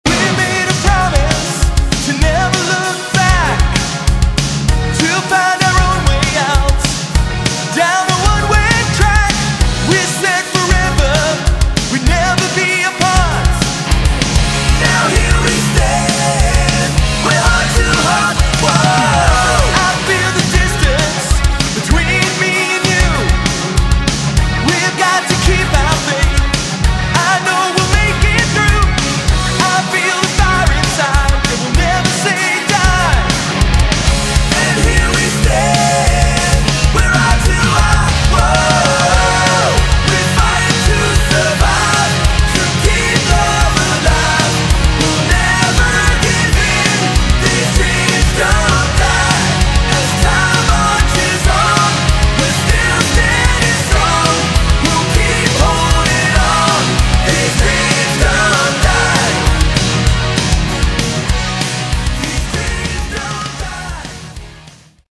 Category: Melodic Rock
lead vocals
keyboards, vocals
guitars
bass
drums